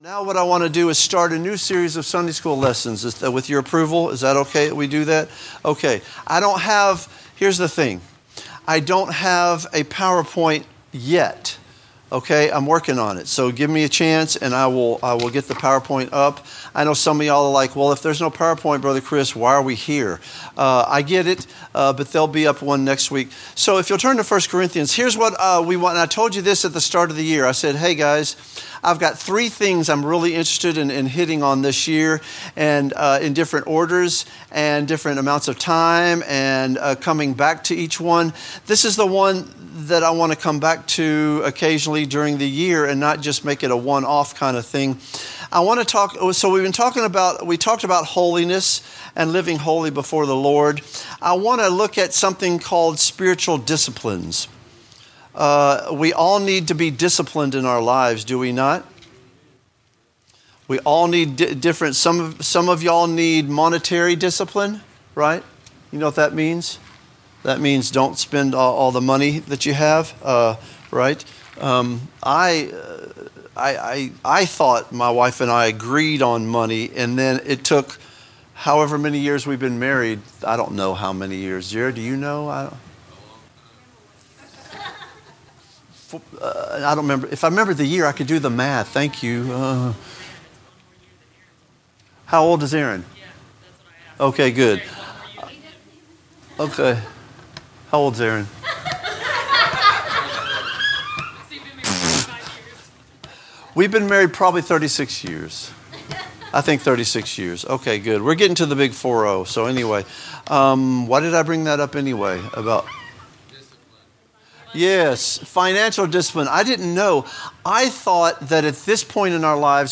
A message from the series "Spiritual Disciplines." A study on what it means for a Christian to live a holy life.